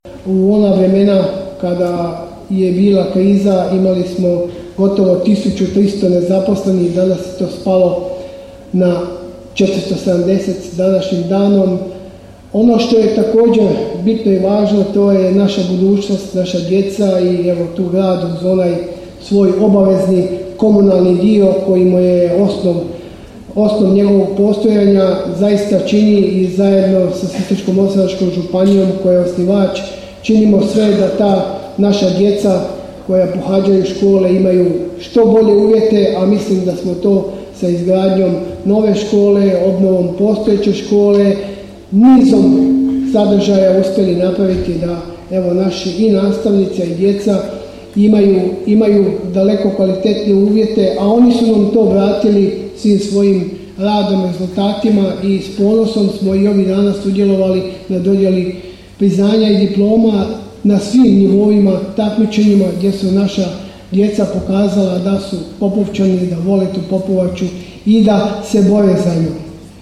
Svečanom sjednicom Gradskog vijeća, Popovača je u srijedu, 21. lipnja 2023. godine proslavila Dan grada i blagdan svoga zaštitnika, sv. Alojzija Gonzage.
Gradonačelnik Josip Mišković osvrnuo se na sve projekte koji se realiziraju, ali i na one koji se planiraju u narednom razdoblju na području Grada Popovače te je zahvalio svima koji iskazuju podršku razvoju Grada zadnjih 30 godina.